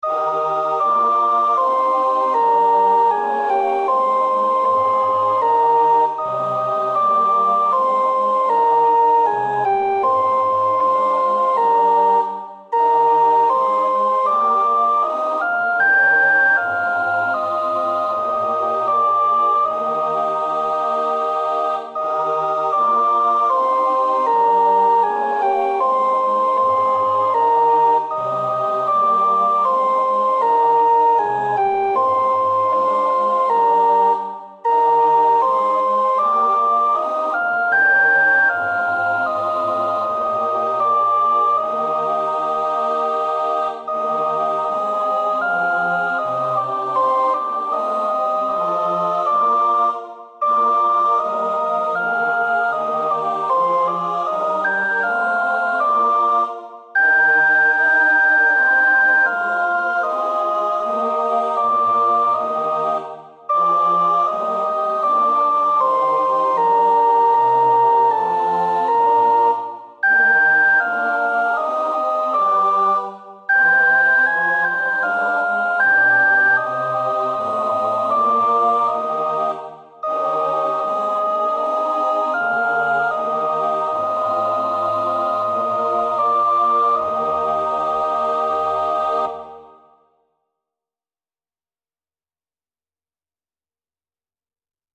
68 Ach Herr, laß dein lieb engelein (sopraan).mp3